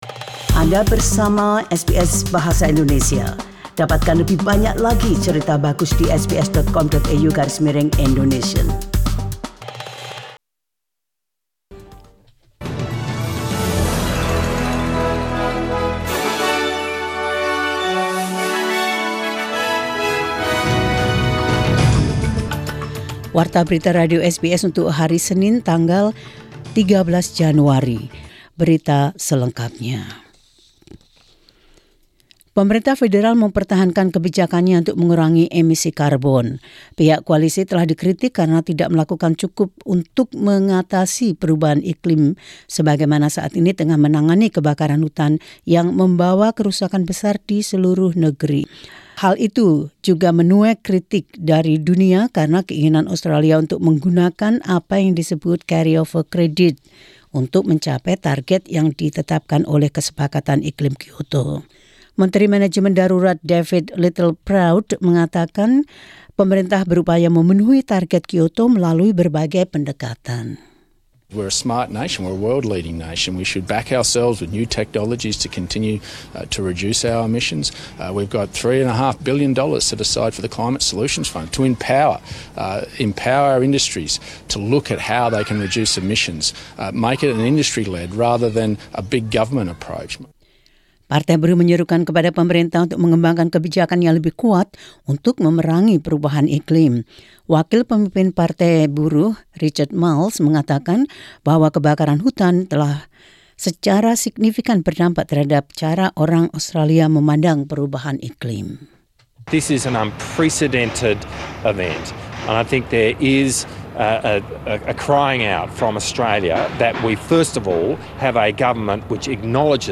SBS Radio News in Indonesian 13 Jan 2020.